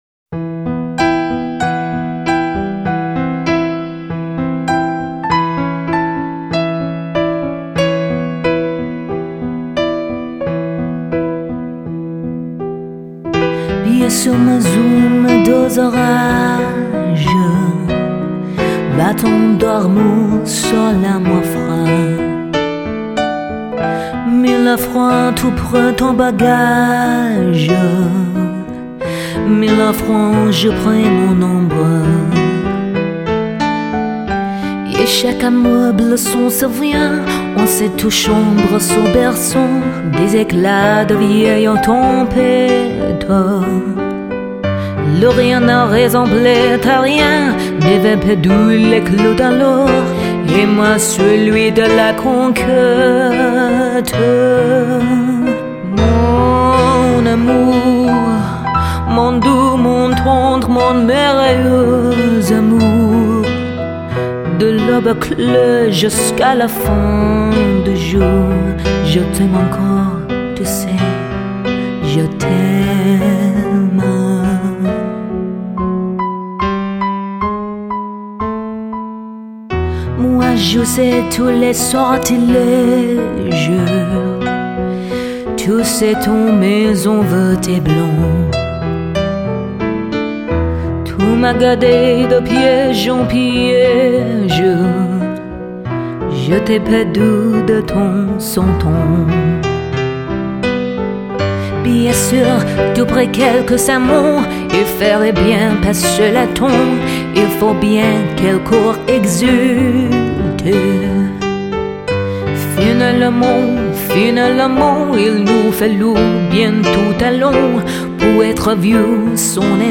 类型:世界音乐